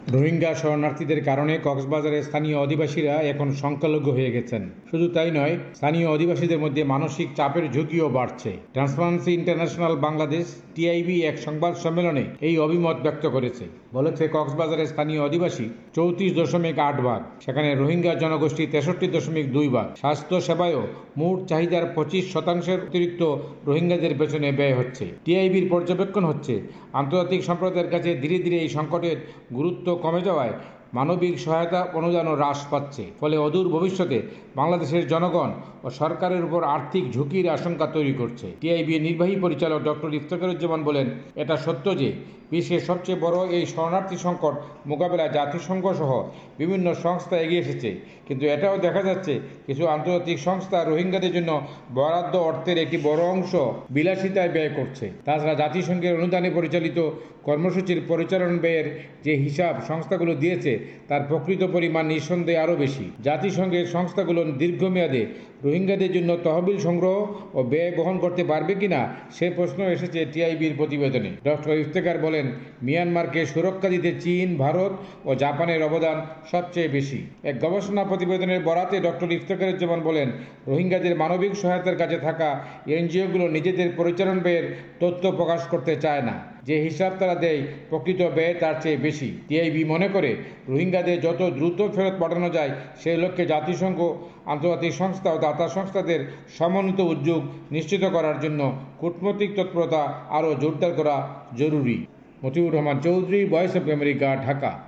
ঢাকা থেকে মতিউর রহমান চৌধুরী'র রিপোর্ট।